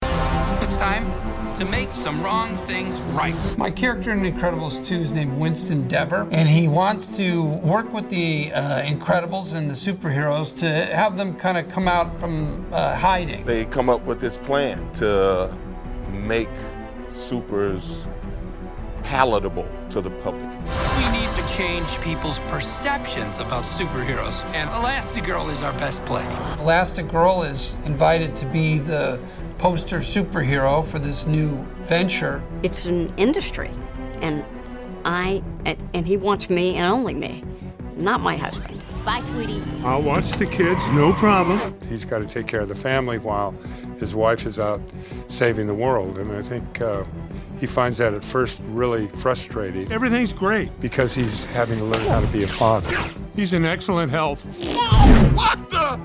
Incredibles 2 Cast Sounds Off on Sequel